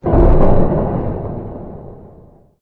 Bang1.ogg